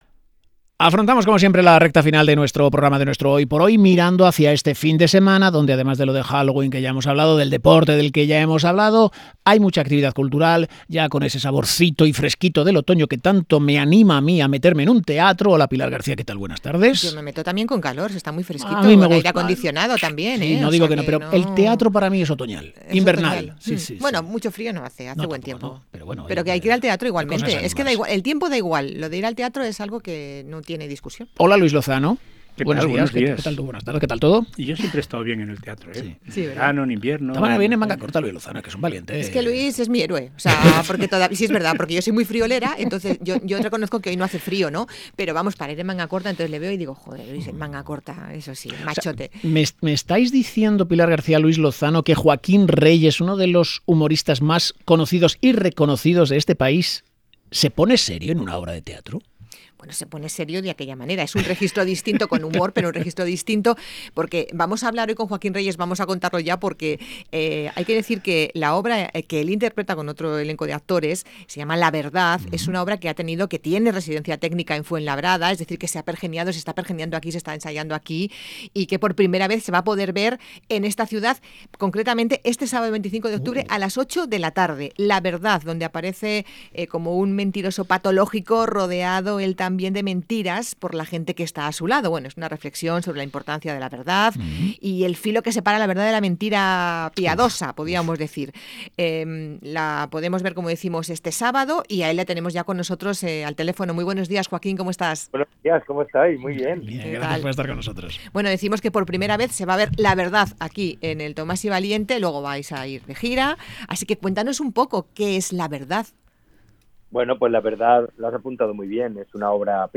Entrevista a Joaquín Reyes, actor y humorista, que llega a Fuenlabrada con la obra ‘La verdad’.